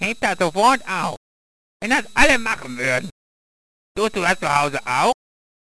Selbstaufgenommene Original Spinatwachtelaufnahme - nun GNU-free.
Beiträge)Bearbeitung der alten Version - mehr Leerlauf zum Schluß.